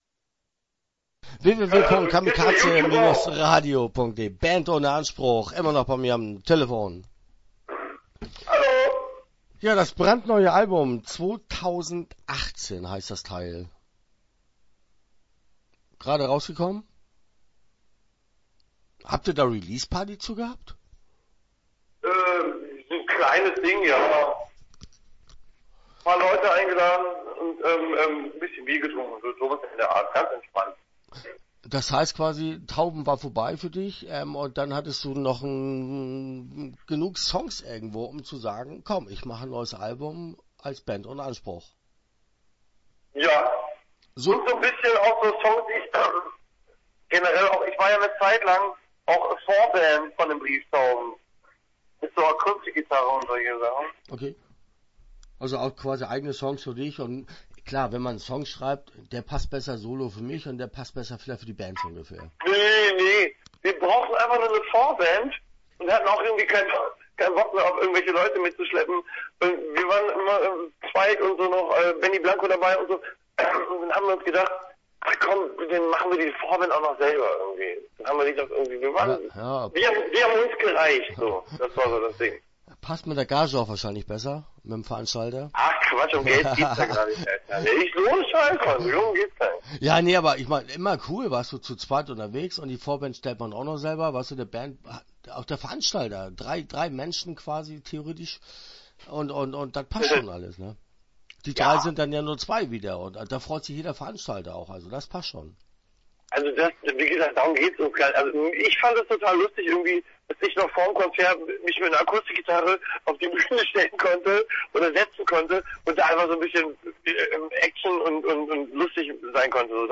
Band ohne Anspruch - Interview Teil 1 (8:15)